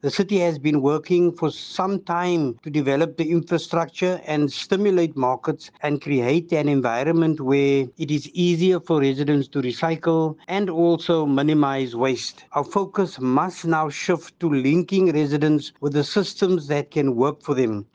Mayoral committee member for Urban Waste Management, Grant Twigg, says the facility is the perfect location to inspire the youth to get involved in recycling and waste minimisation: Play sound: Stay tuned for more news………….